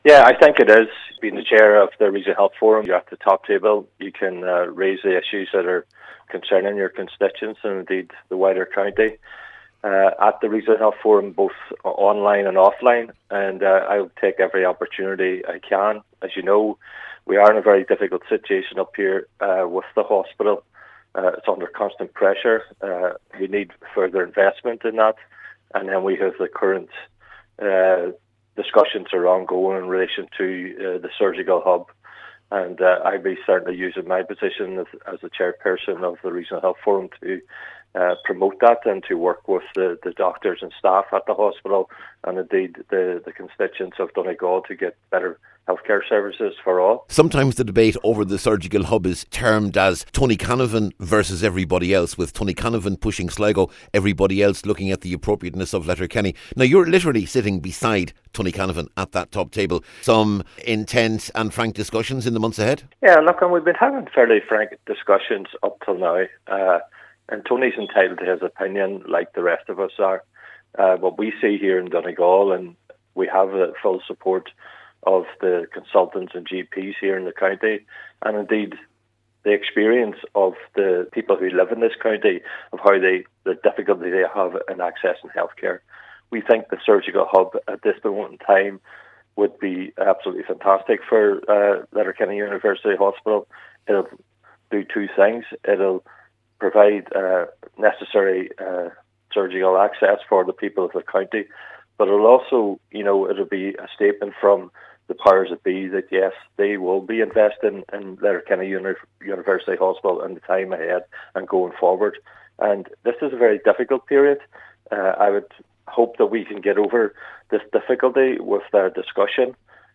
Hear the full interview here, where Cllr McMonagle begins by answering the question ‘Is this a particularly good time to take the chair?’